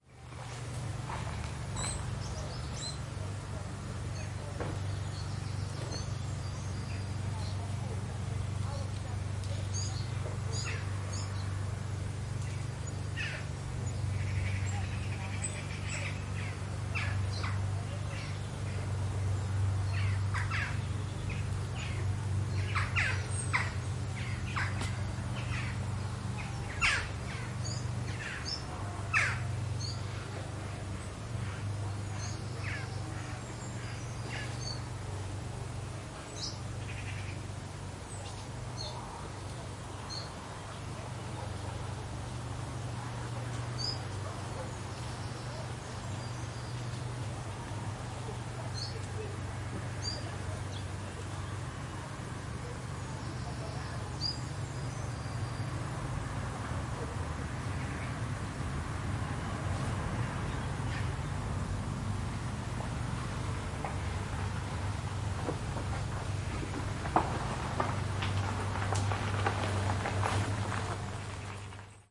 描述：在Callantsoog进行现场记录。缩放h4n，xy
Tag: 现场录音 拖拉机 音景 氛围 环境 背景声 农田 氛围 卡兰茨奥赫 荷兰 一般的噪声 荷兰 夏季